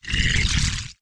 dragon_ack2.wav